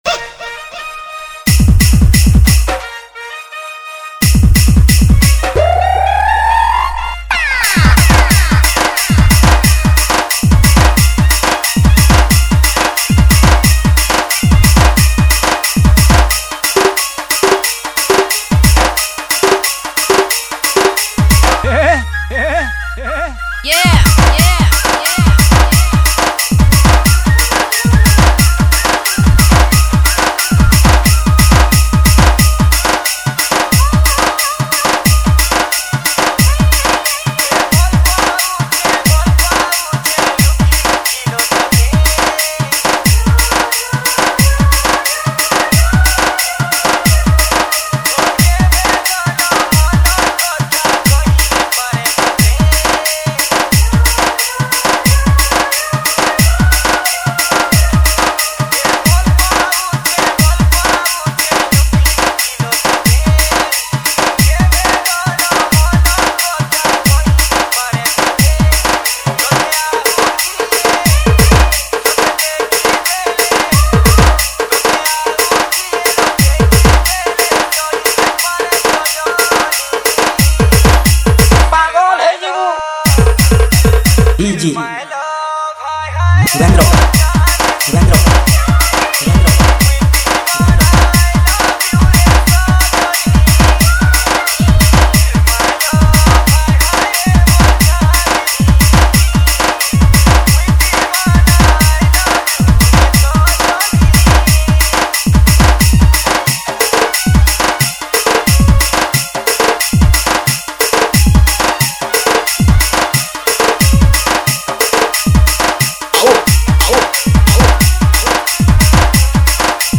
SAMBALPURI LOVE DJ REMIX